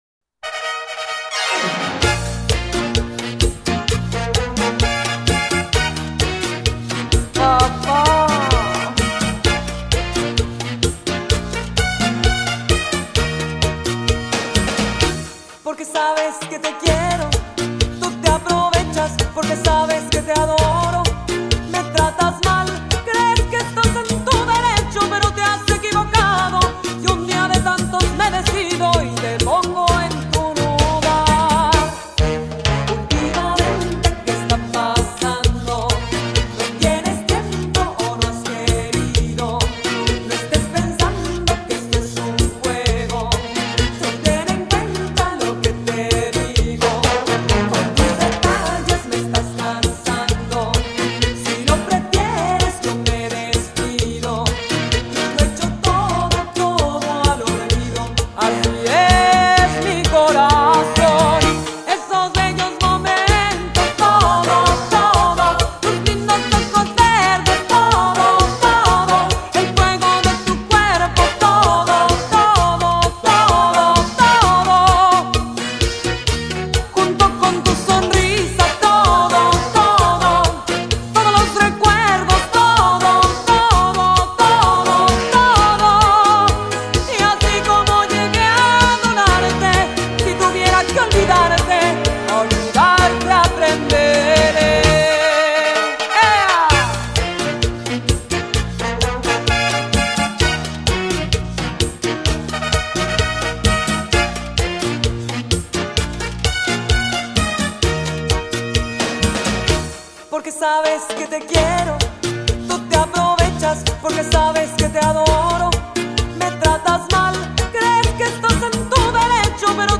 03 Cha-Cha-Cha